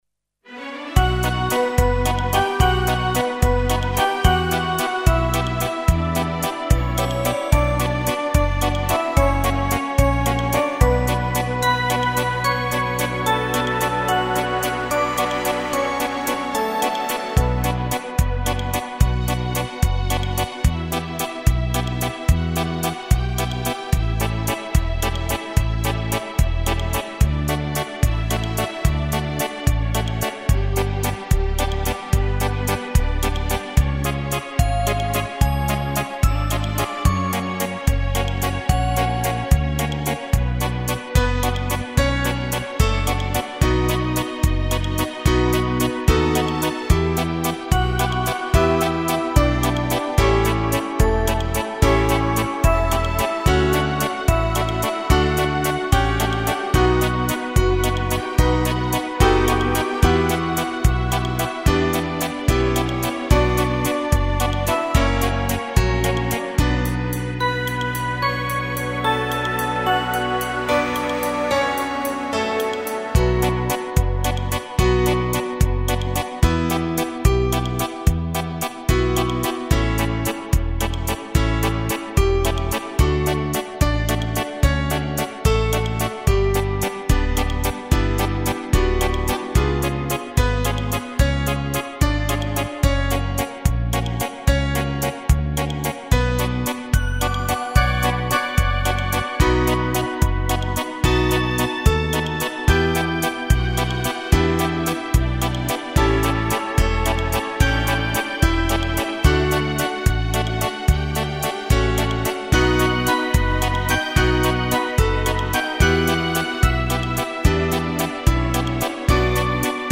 журавлина пісня мінус.mp3